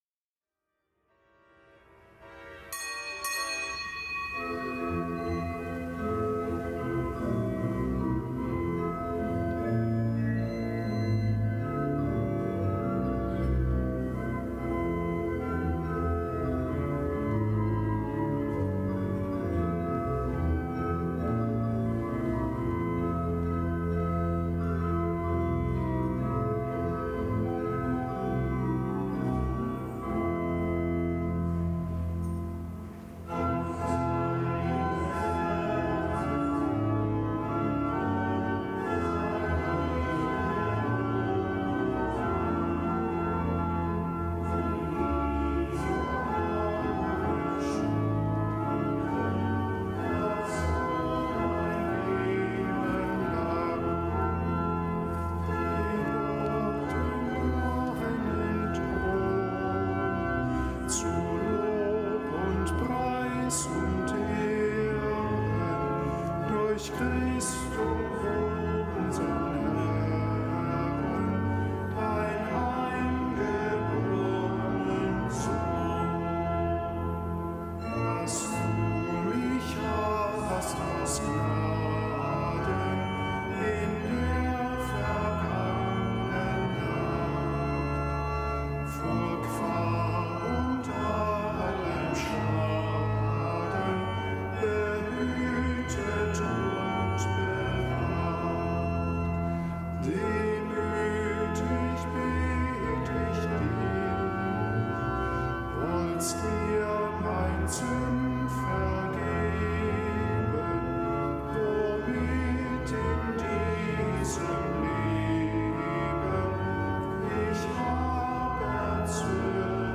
Kapitelsmesse aus dem Kölner Dom am Donnerstag der fünften Woche im Jahreskreis.